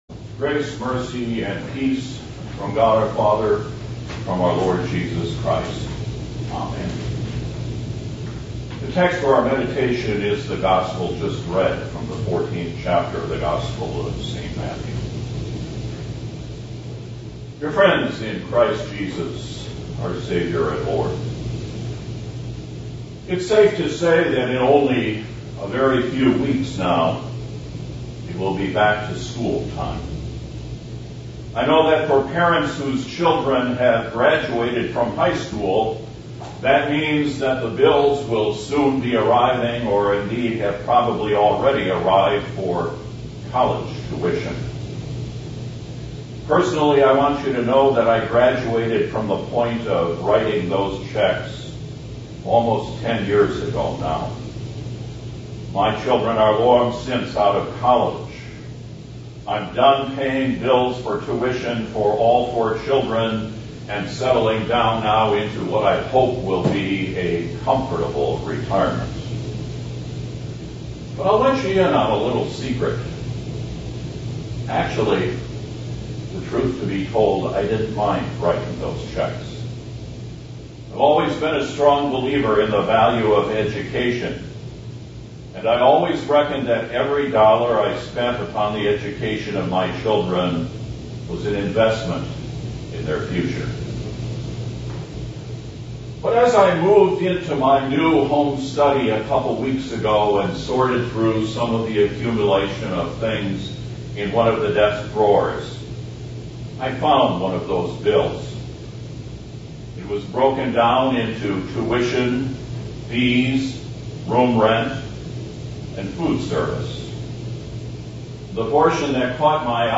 Trinity 6 / Pentecost 13A 2011 – Guest Preacher